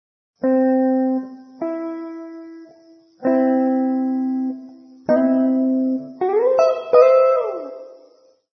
Si tenga conto preventivamente del fatto che, per convenzione, si definisce un'intervallo di terza maggiore un'intervallo di quattro semitoni, ad esempio l'intervallo tra C e E [